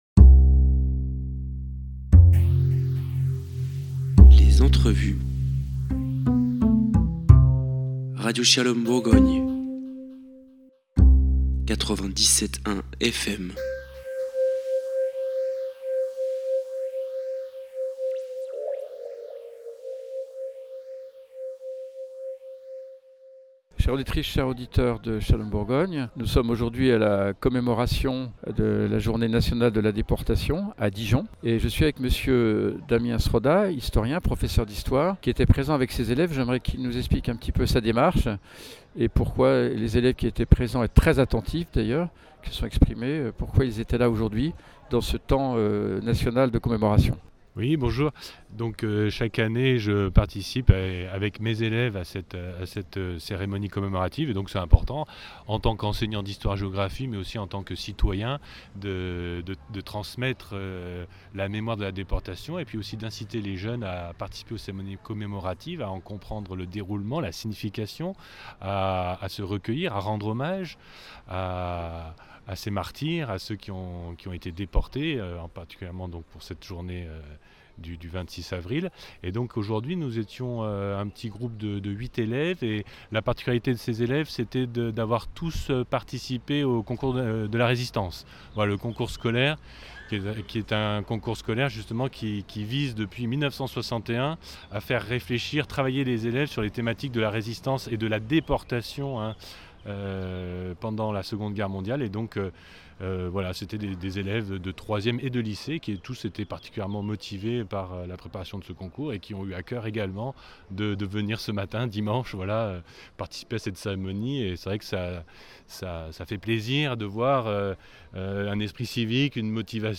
A Dijon, place Edmond Debeaumarché, Denis Bruel, sous -préfet de la Côte d'Or avait convié les autorités civiles et militaires et les dijonnais à la cérémonie en hommage aux victimes et aux héros de la déportation.
Le "Chant des marais" a conclu la cérémonie.
Reportage et interview